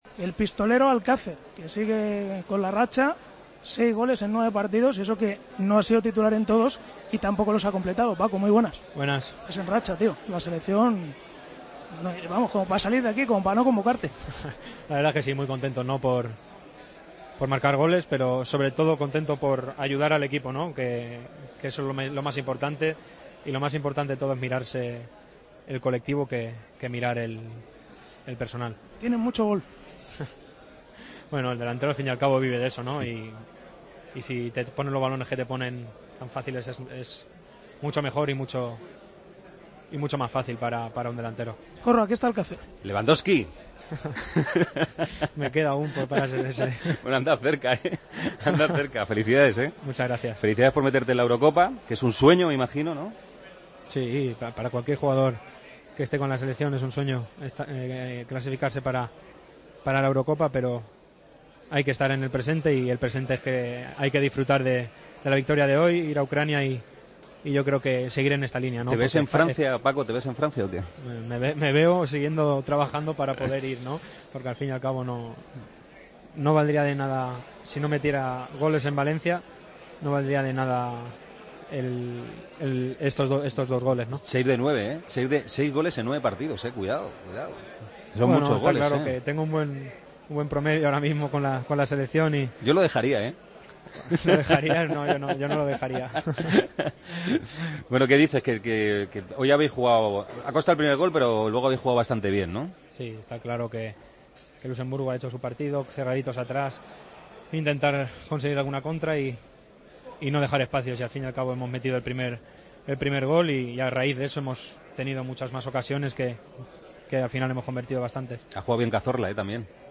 El jugador de la selección española habló del partido contra Luxemburgo y su racha goleadora con el combinado nacional :"si no marco también con el Valencia, no sirve para nada".